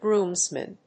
groomsmen.mp3